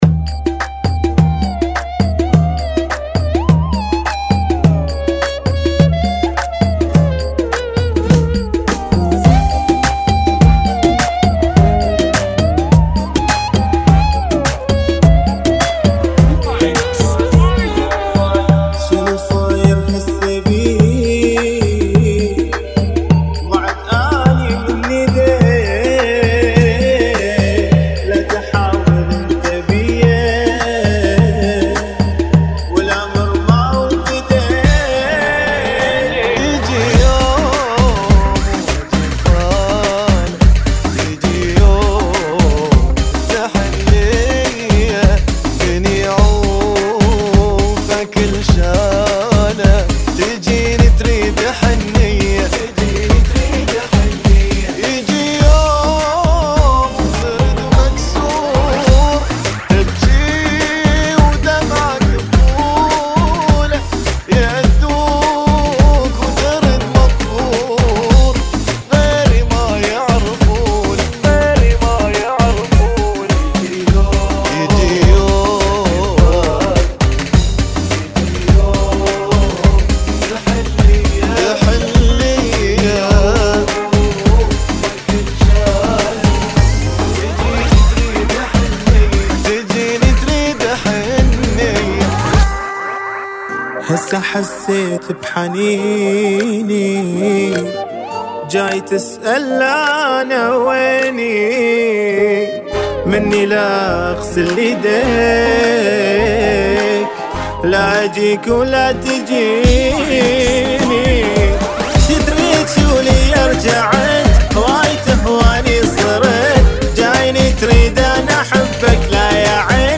104 Bpm